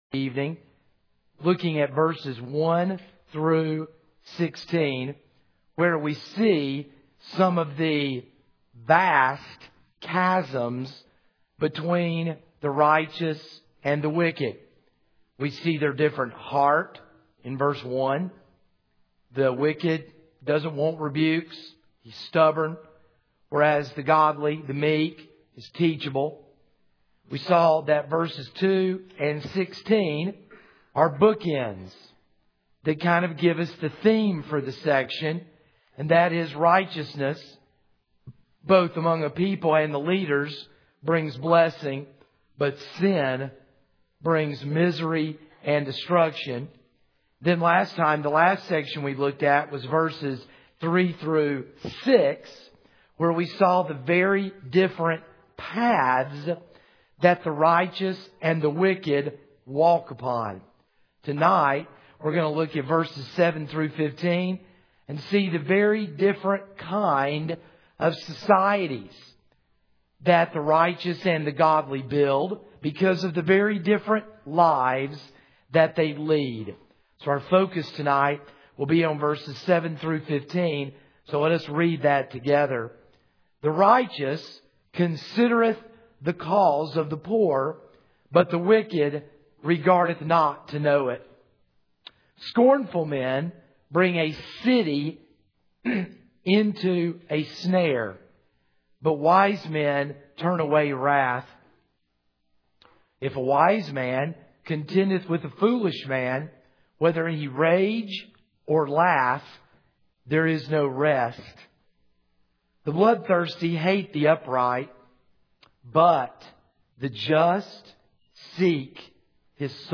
This is a sermon on Proverbs 29:1-16 (Part 2 of 2).